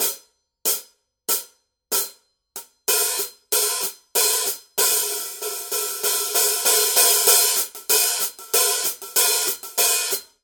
Paiste 14" PST 8 Reflector Sound Edge Hi-Hat Cymbals | Nicko's Drum One